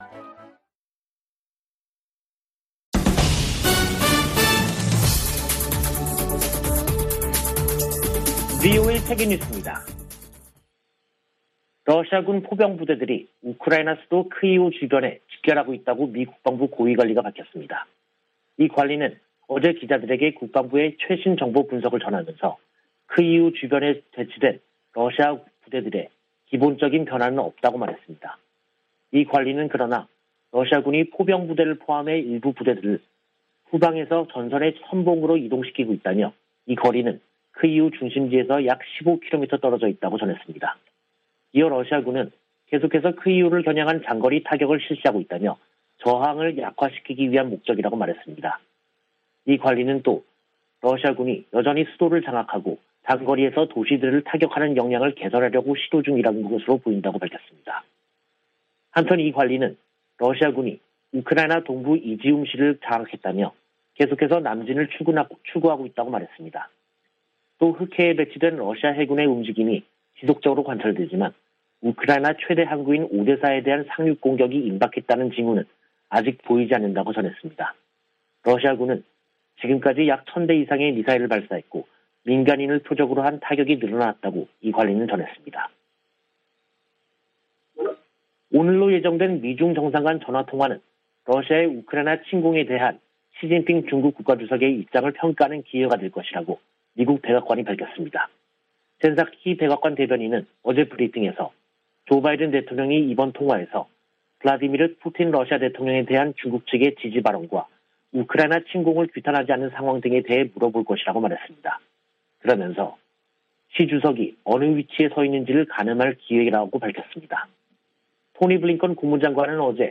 VOA 한국어 간판 뉴스 프로그램 '뉴스 투데이', 2022년 3월 18일 2부 방송입니다. 백악관은 북한의 미사일 발사를 거듭 규탄하고 한일 양국 방어 의지를 재확인했습니다. 한국의 윤석열 차기 정부가 현 정부보다 미국의 정책에 더 부합하는 외교정책을 추구할 것으로 미 의회조사국이 분석했습니다. 토마스 오헤아 퀸타나 유엔 북한인권특별보고관은 지난 6년 동안 북한 인권 상황이 더욱 악화했다고 평가했습니다.